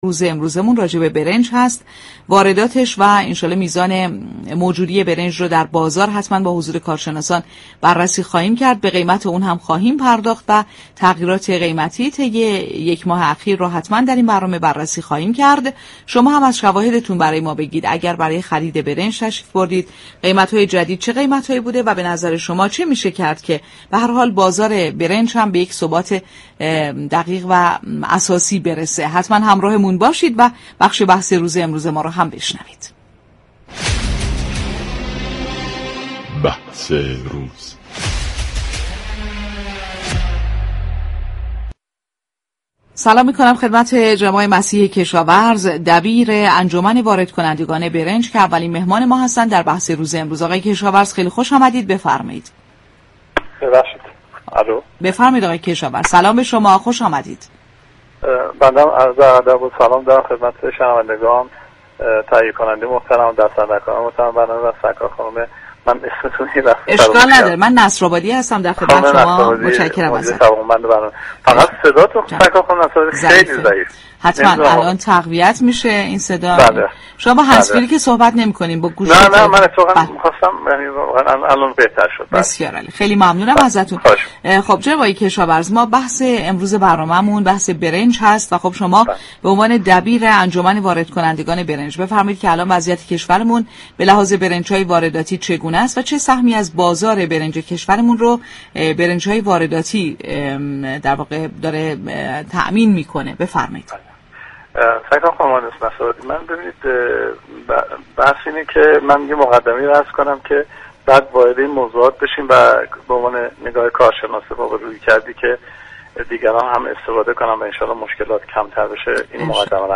در گفت و گو با بازار تهران رادیو تهران